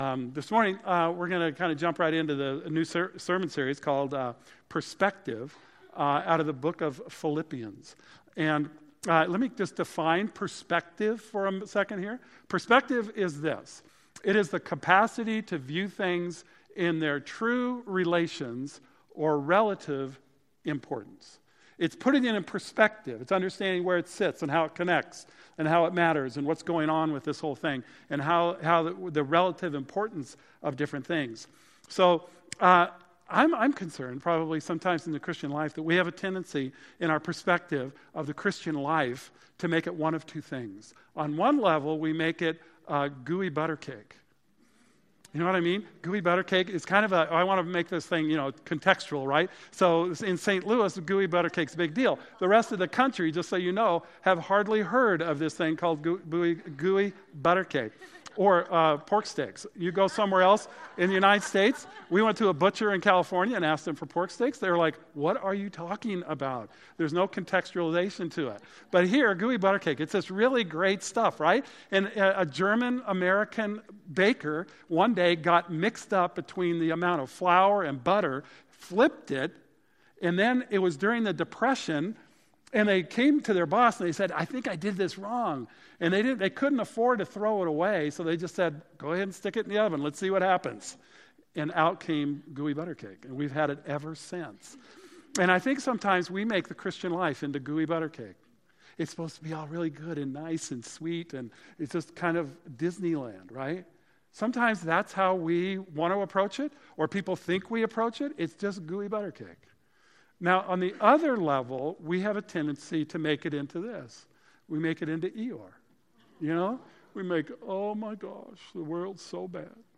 We started a new series studying the book of Philippians this morning. Unfortunately, there is no sermon video to accompany the message.